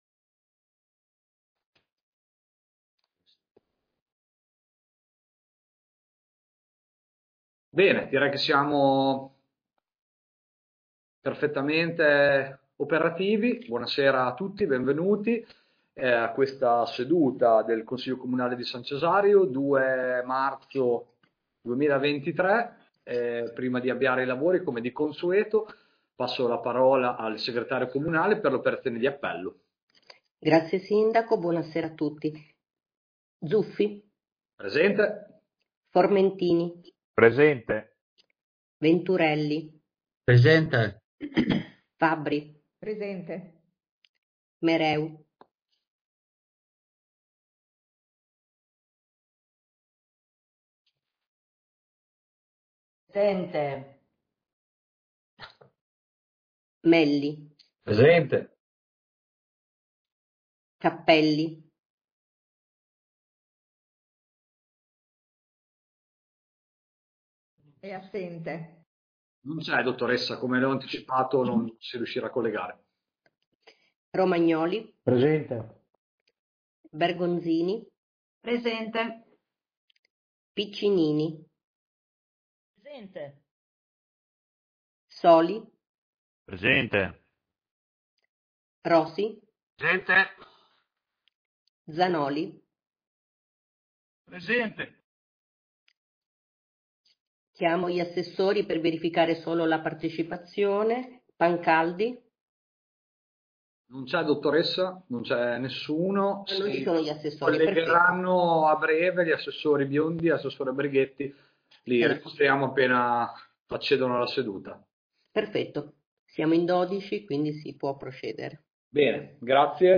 Consiglio Comunale del 2 marzo 2023